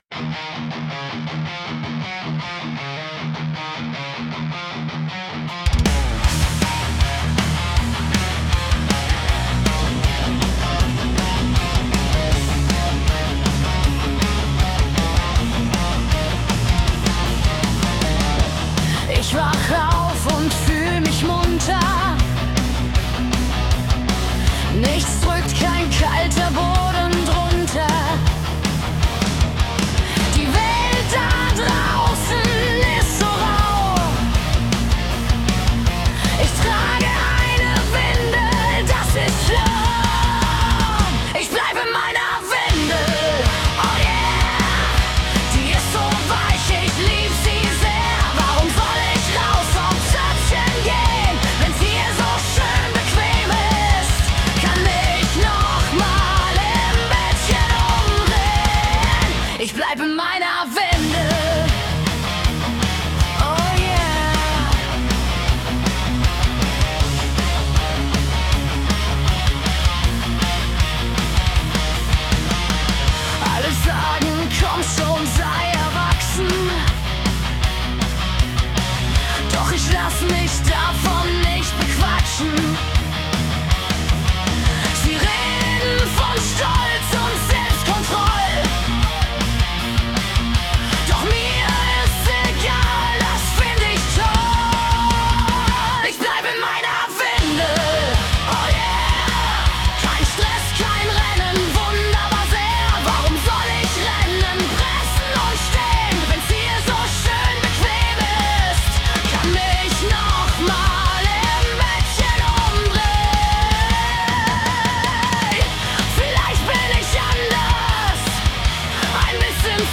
Vocal: KI (Suno Premier)